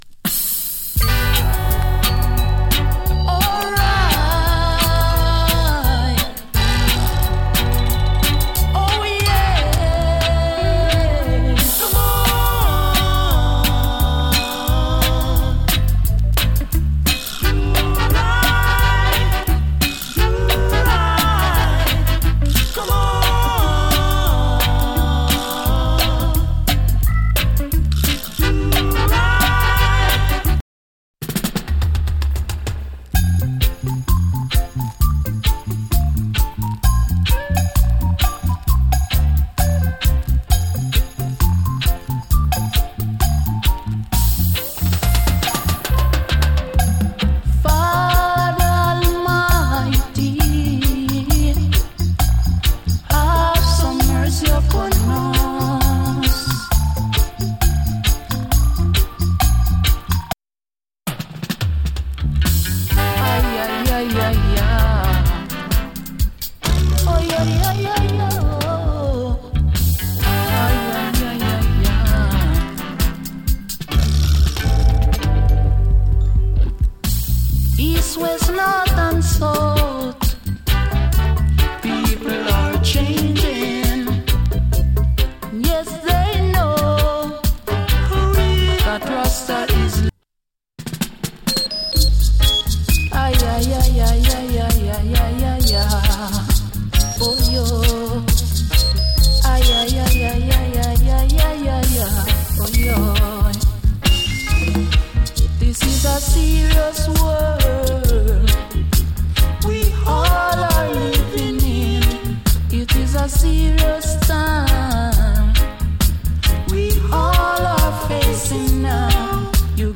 チリ、パチノイズ極わずかに有り。
の牧歌的で美しいコーラスの REGGAE. ROOTS REGGAE ALBUM !